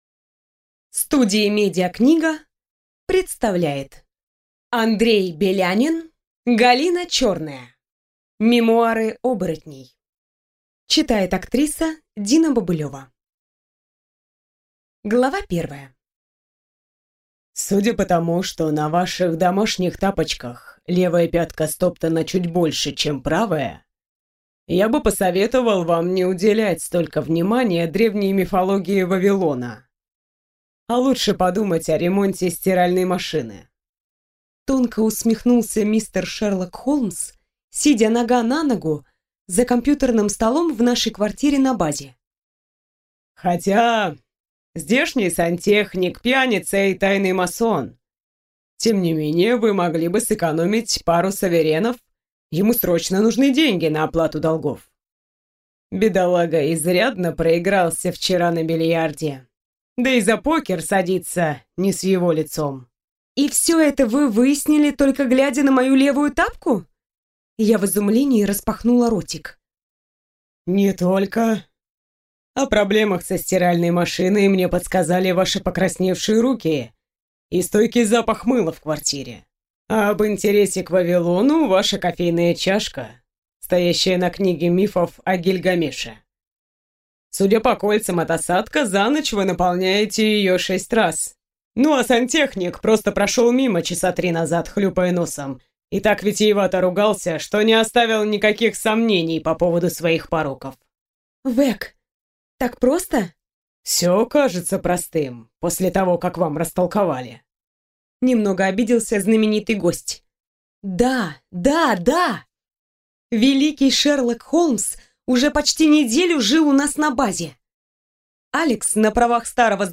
Аудиокнига Мемуары оборотней | Библиотека аудиокниг